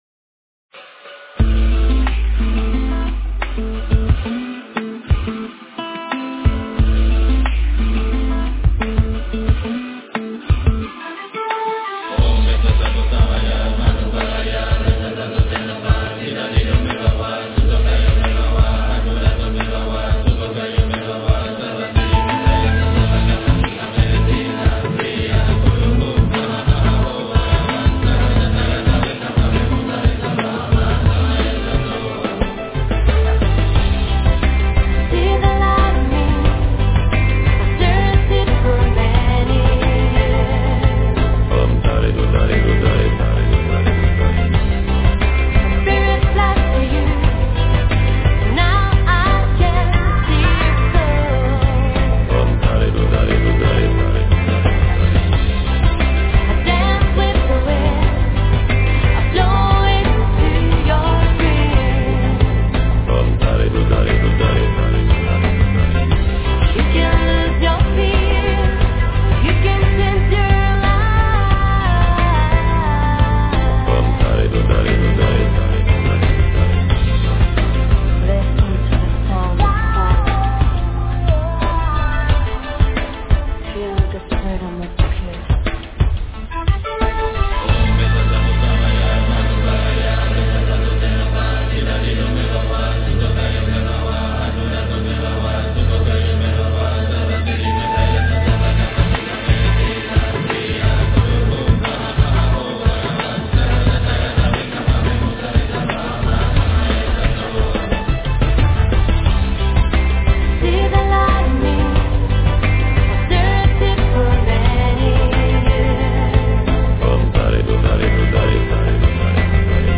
标签: 佛音凡歌佛教音乐